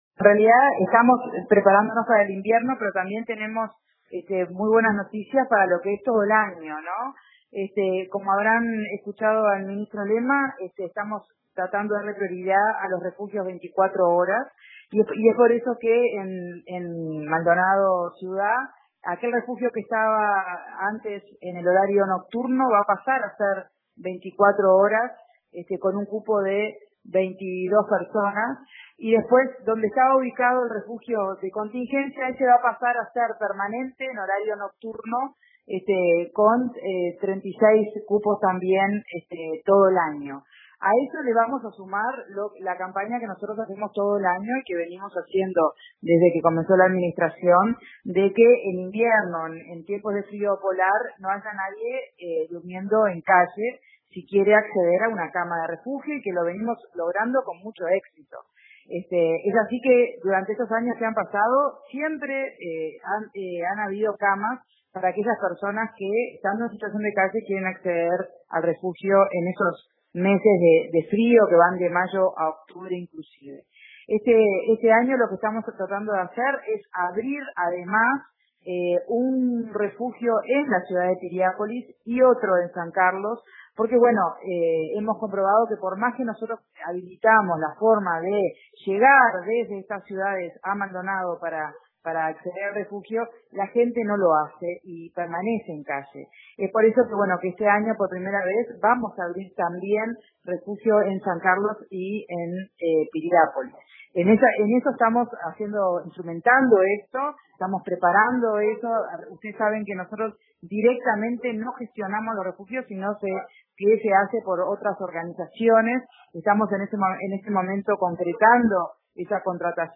El lugar ya está elegido, dijo a RADIO RBC, Magdalena Zumarán, Directora Departamental del MIDES.